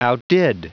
Prononciation du mot outdid en anglais (fichier audio)
Prononciation du mot : outdid